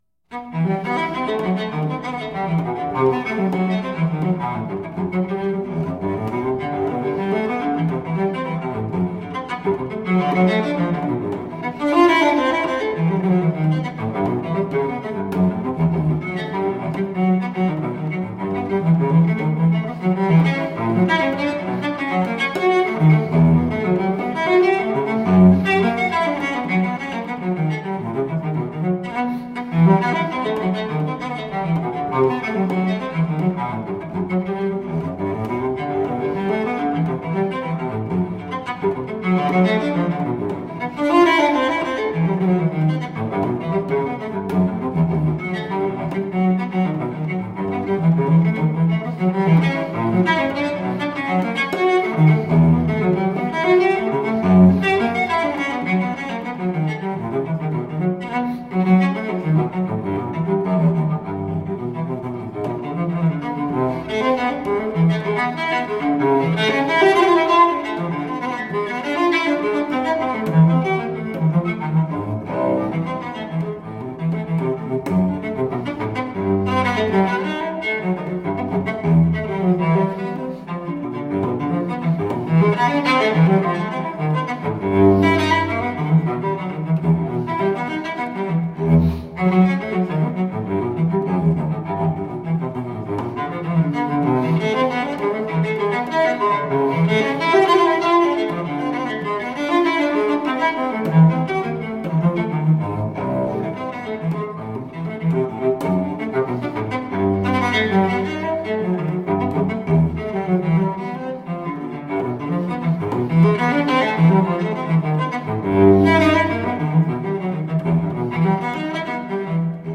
Classical, Baroque, Instrumental, Cello